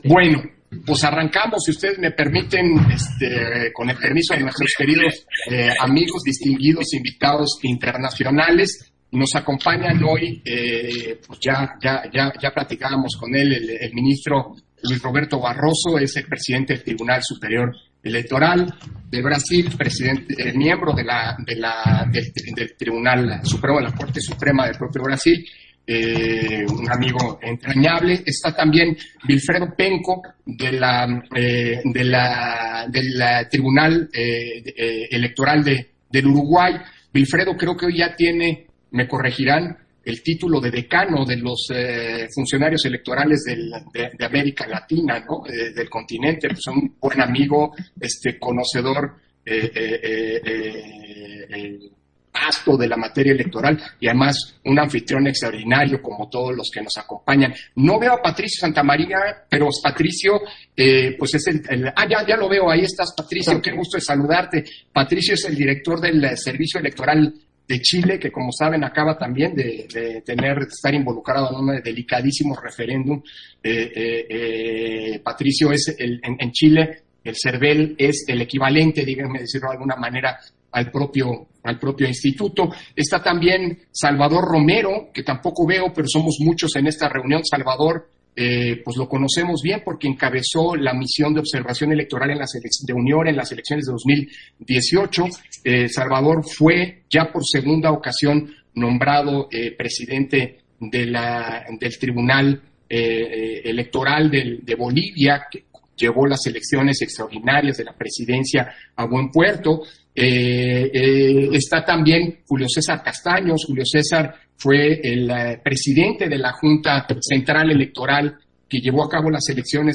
190221_AUDIO_INTERVENCIÓN-DEL-CONSEJERO-PDTE.-CÓRDOVA-INTERCAMBIO-INTERNACIONAL
Intervenciones de Lorenzo Córdova, en el Intercambio Internacional con Autoridades Electorales de México sobre esquemas de comunicación respecto a medidas sanitarias en los lugares de votación